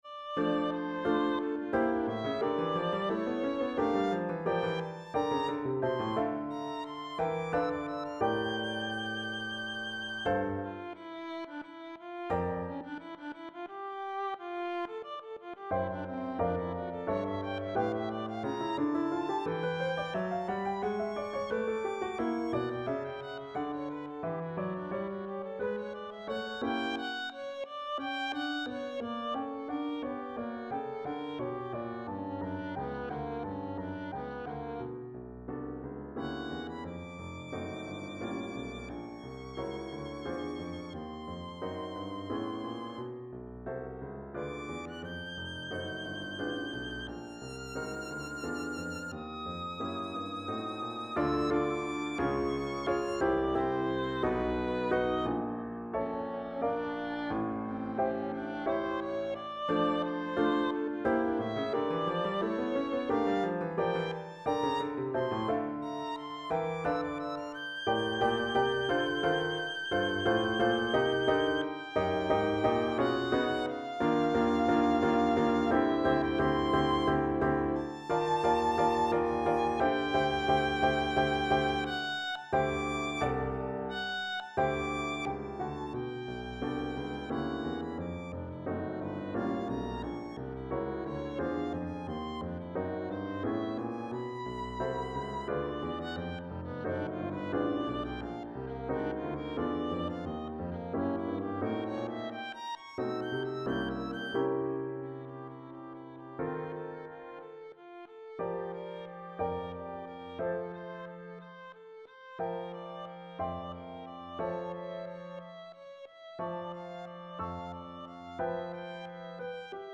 Viulusonatiini osa 1 1.mp3